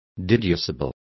Complete with pronunciation of the translation of deducible.